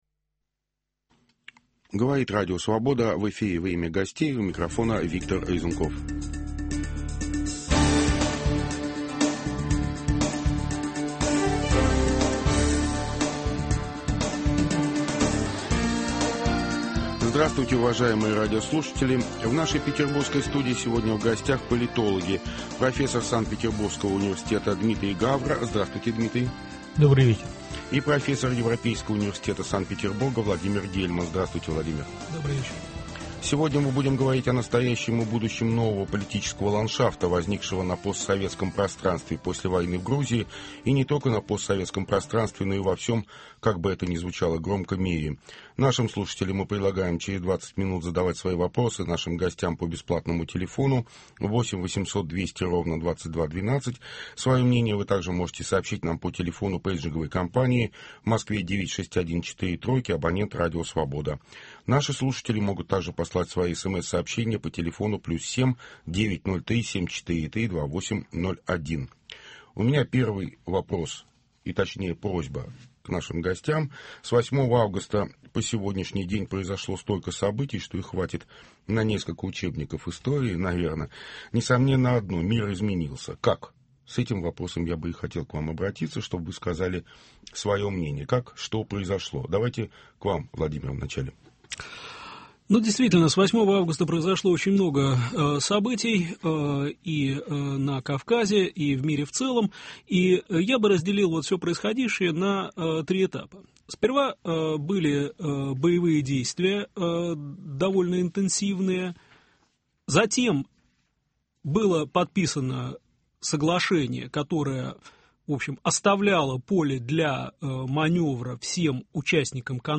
Петербургские политологи обсуждают настоящее и будущее нового политического ландшафта, возникшего на постсоветском пространстве после войны в Грузии.